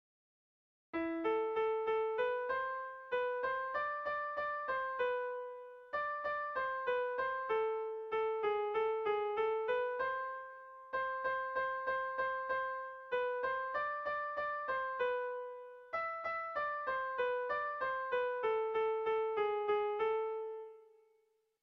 Air de bertsos - Voir fiche   Pour savoir plus sur cette section
Kontakizunezkoa
ABDE